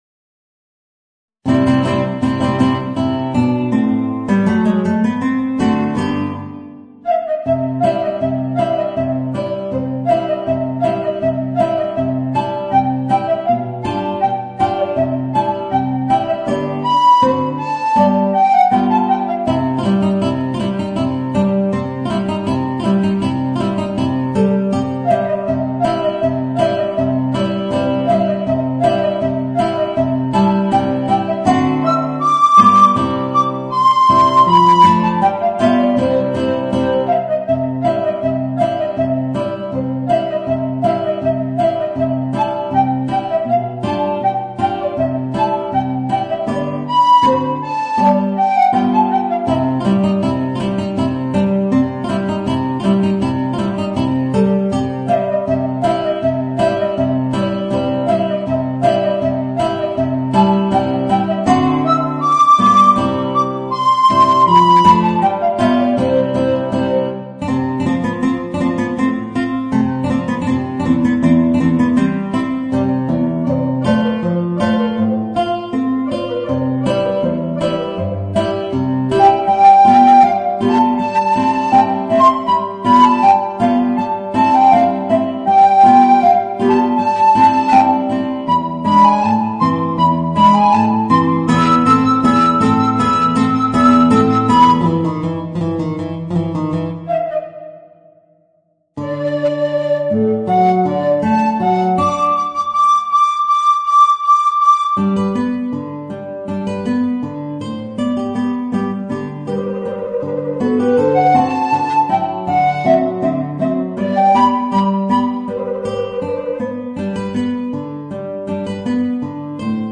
Voicing: Alto Recorder and Guitar